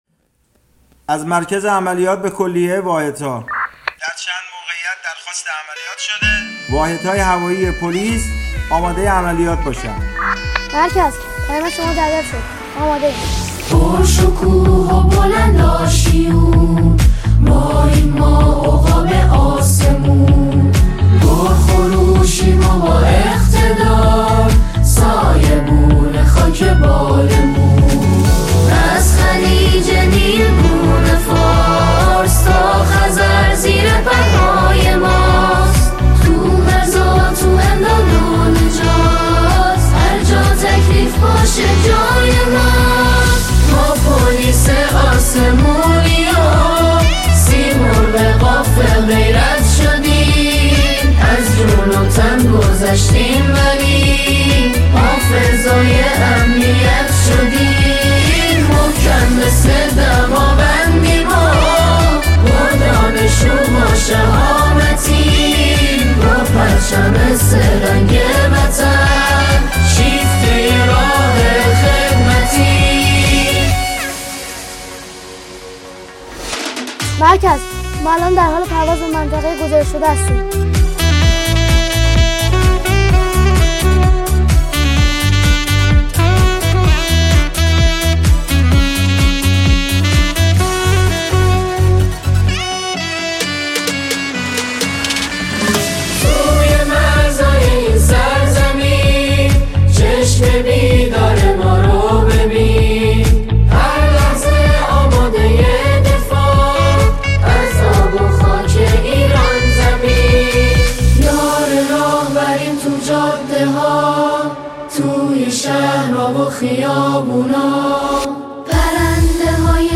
نماهنگ پرشور و اقتدارآفرین
ژانر: سرود